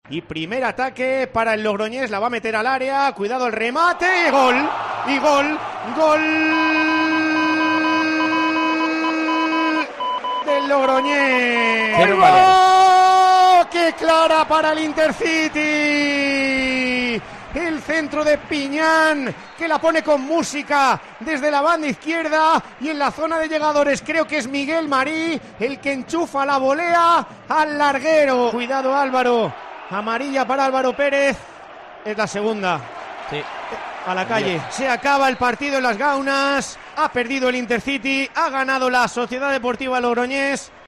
Sonidos de la derrota del Intercity ante la SD Logroñés